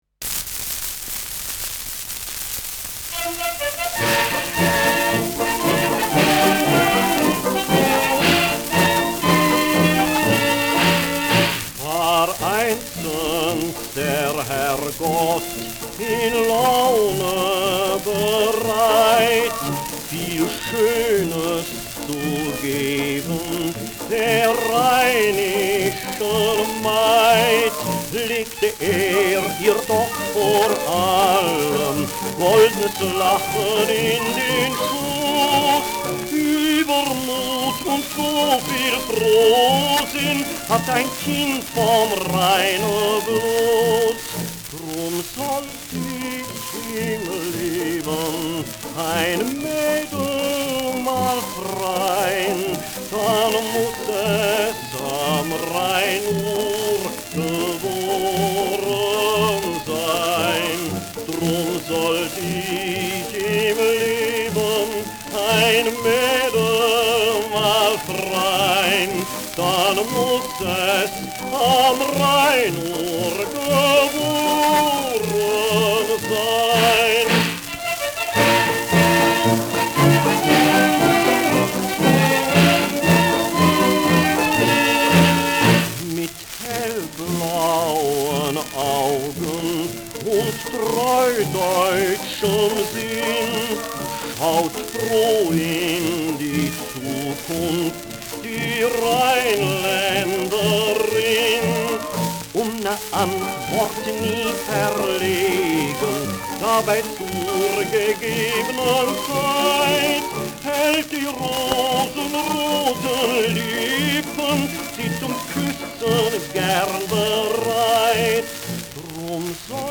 Schellackplatte
Bariton : mit Orchester